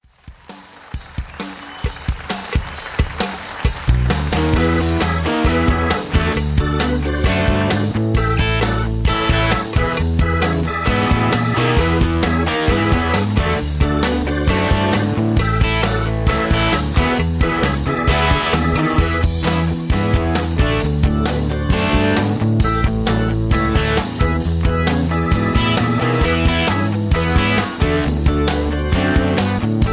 Tags: karaoke, backing tracks